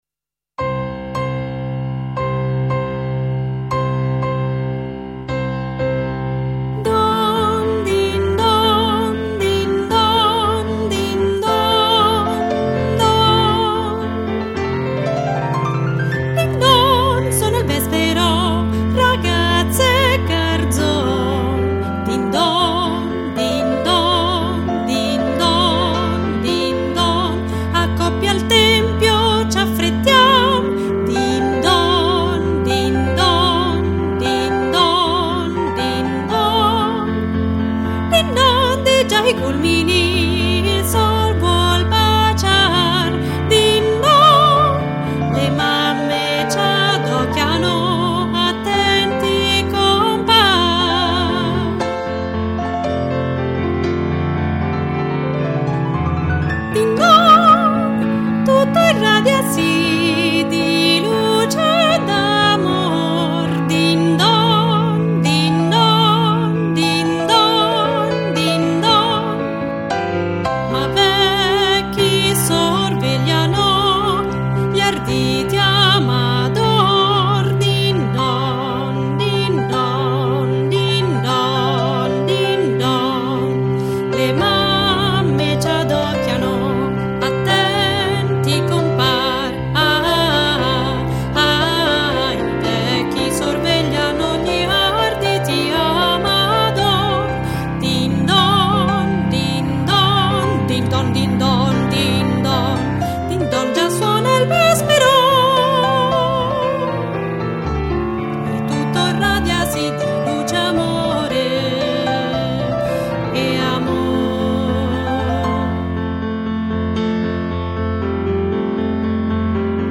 Don Din Don – Ritmica